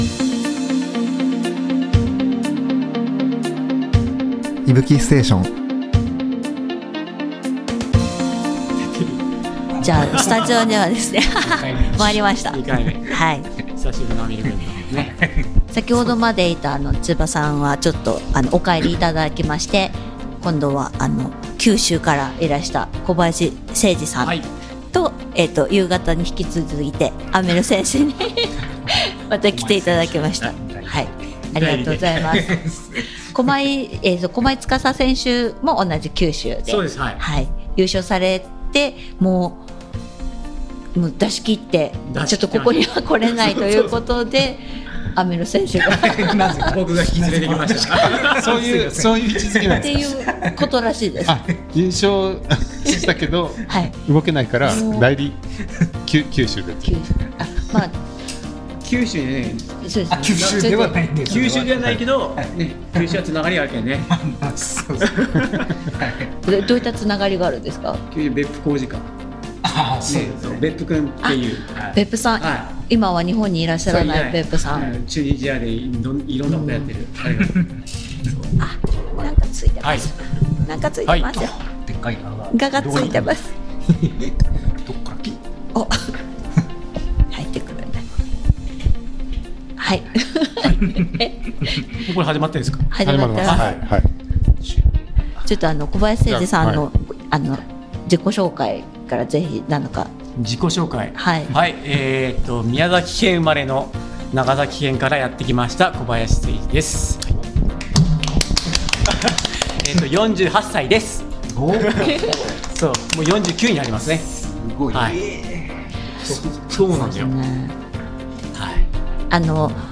Deep Japan Ultra 100公式Liveから、選り抜きの内容をポッドキャストでお届けします！
Audio Channels: 2 (stereo)